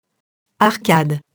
arcade [arkad]